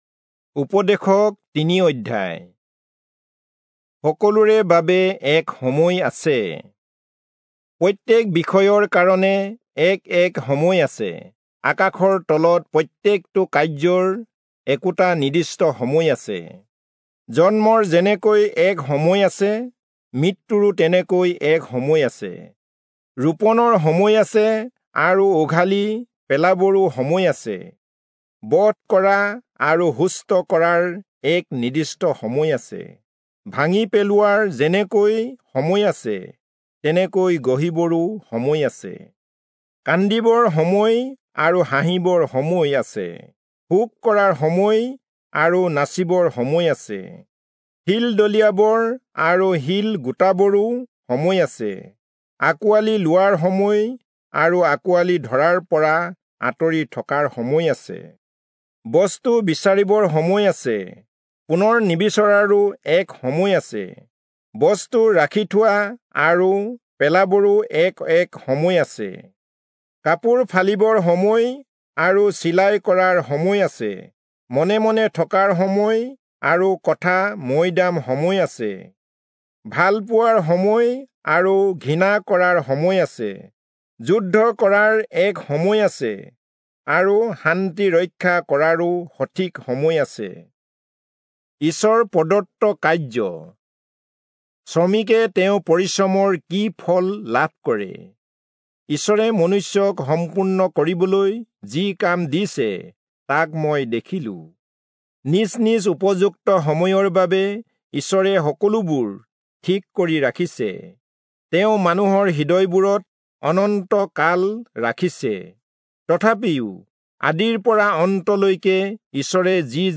Assamese Audio Bible - Ecclesiastes 10 in Tov bible version